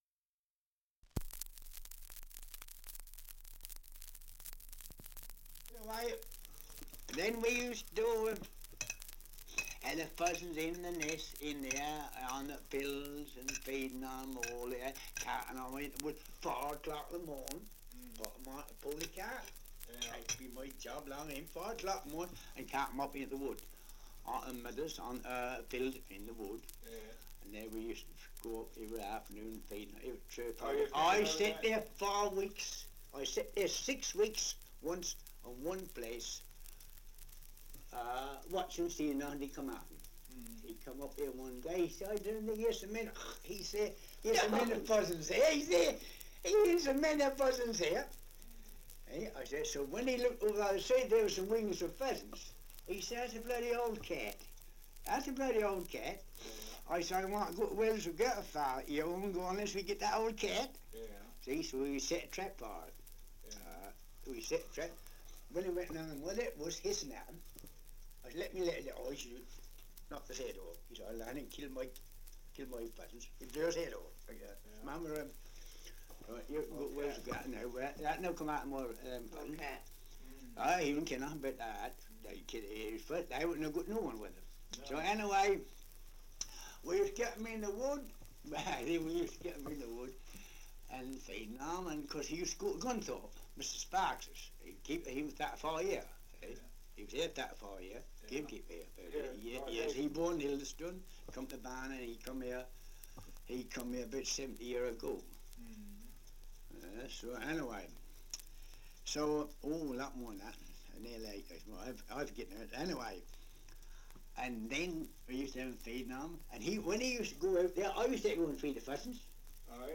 2 - Survey of English Dialects recording in Great Snoring, Norfolk
78 r.p.m., cellulose nitrate on aluminium